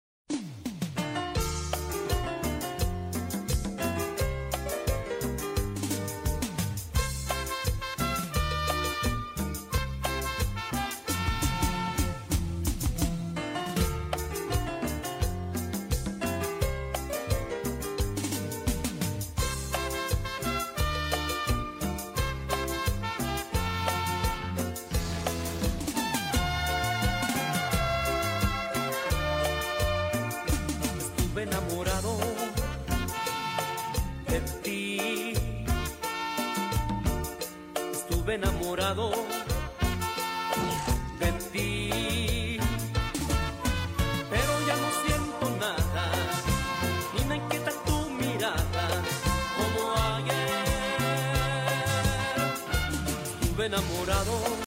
cumbia boliviana